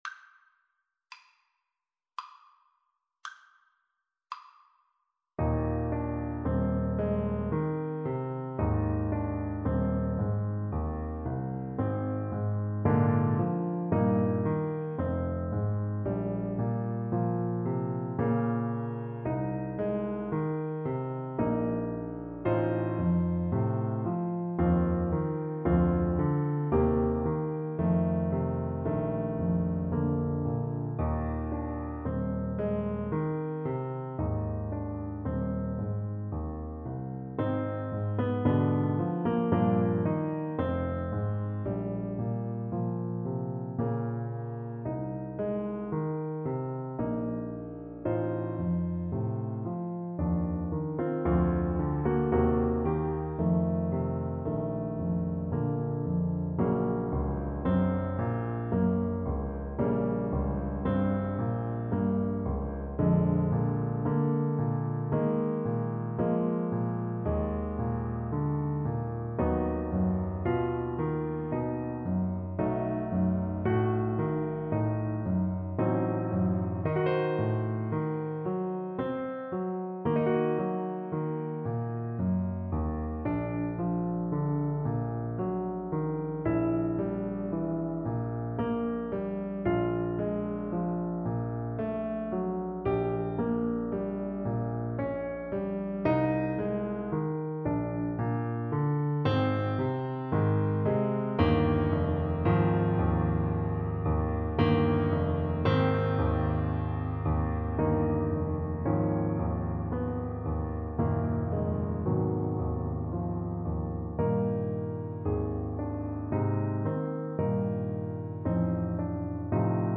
Classical Brahms, Johannes Intermezzo, A Major Op.118 No.2 Alto Saxophone version
Alto Saxophone
Eb major (Sounding Pitch) C major (Alto Saxophone in Eb) (View more Eb major Music for Saxophone )
Andante teneramente = 75
3/4 (View more 3/4 Music)
Classical (View more Classical Saxophone Music)
brahms_intermezzo_op_118_2_ASAX_kar3.mp3